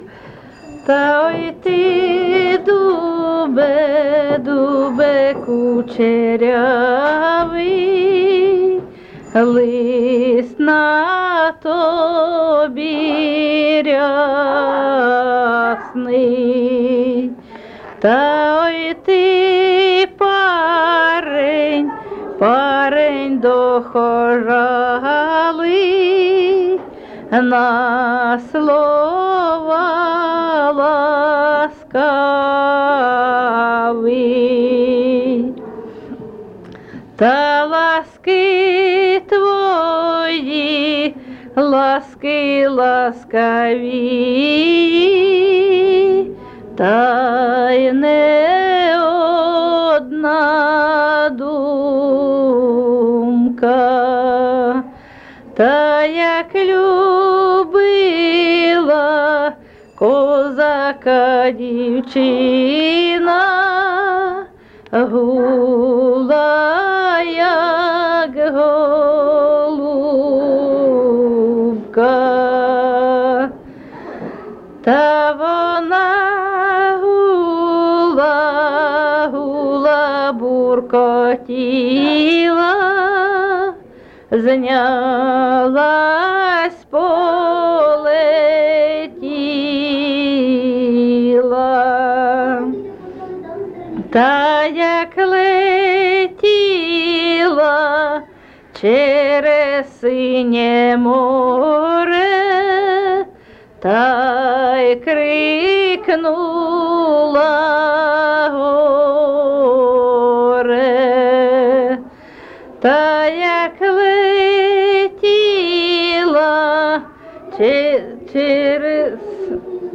Місце записус. Лиман, Зміївський (Чугуївський) район, Харківська обл., Україна, Слобожанщина